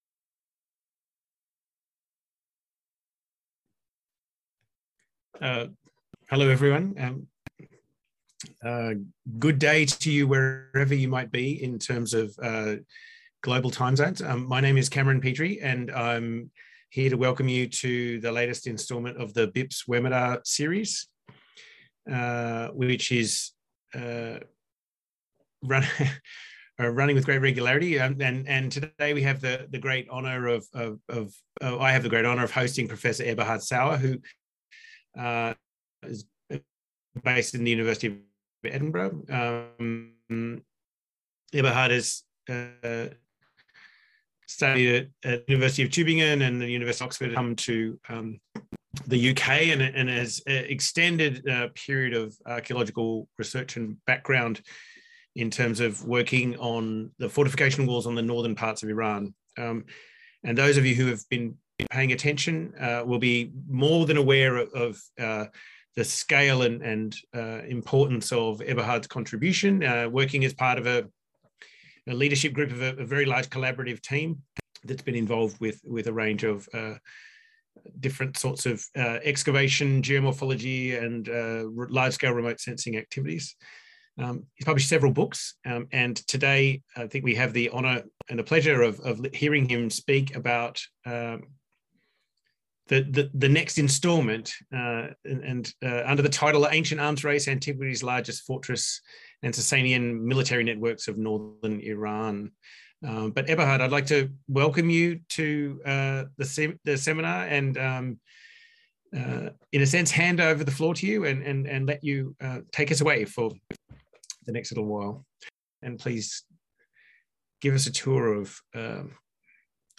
In this webinar